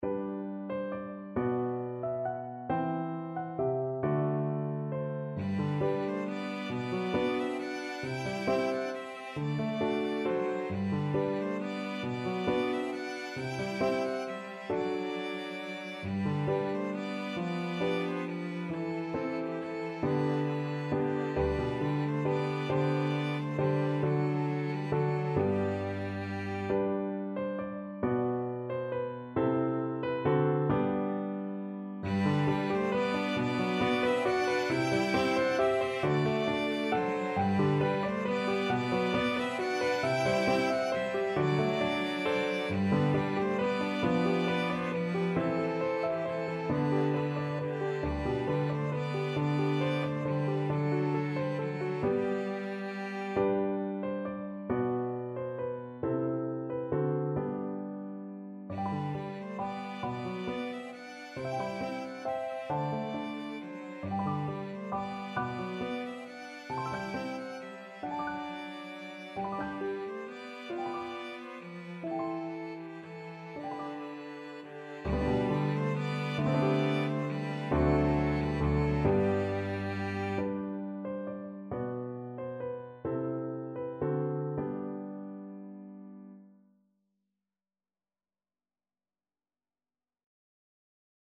Violin 1Cello
.=45 Gently Lilting .=c.45
6/8 (View more 6/8 Music)